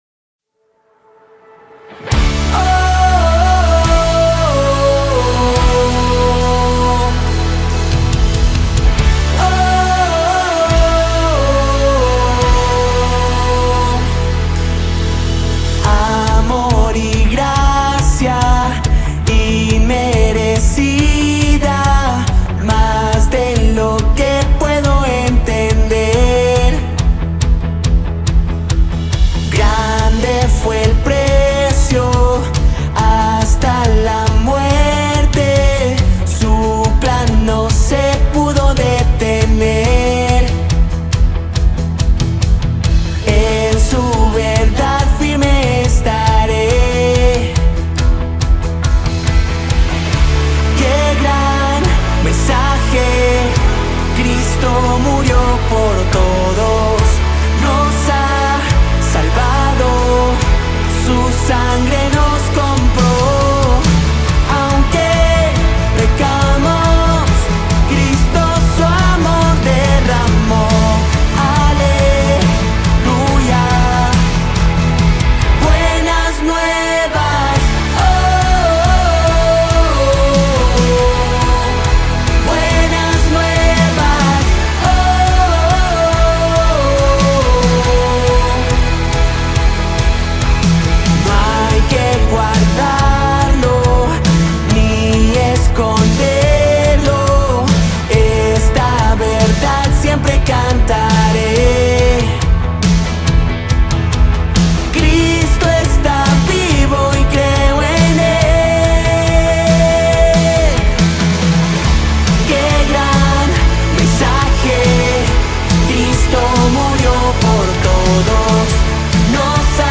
44 просмотра 24 прослушивания 0 скачиваний BPM: 140